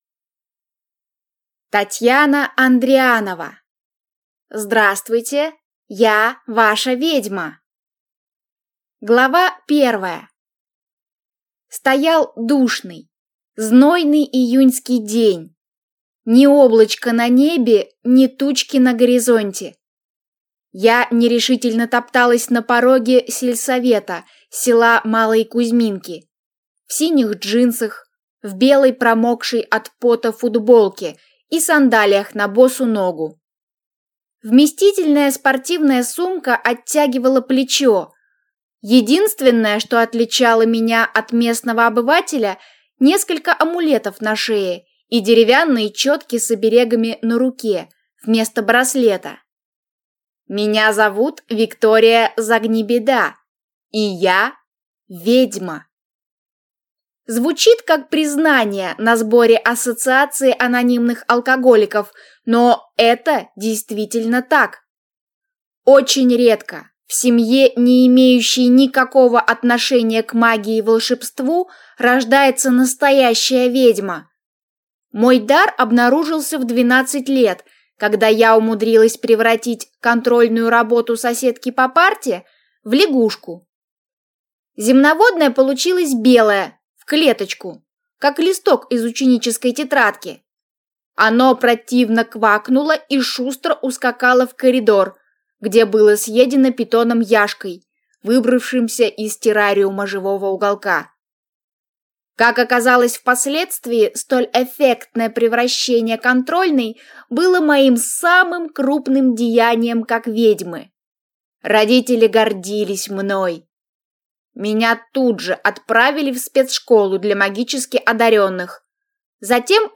Аудиокнига Здравствуйте, я ваша ведьма!